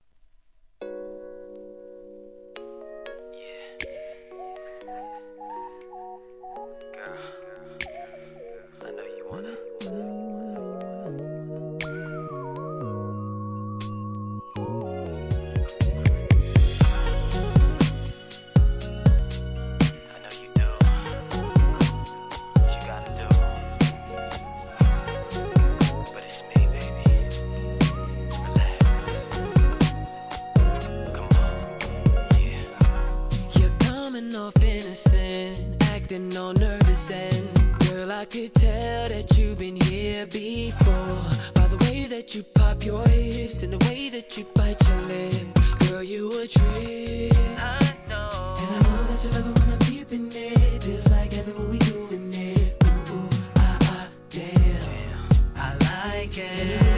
HIP HOP/R&B
アルバムの中でも人気のメロ〜ナンバー♪